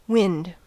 En-us-wind-air.ogg.mp3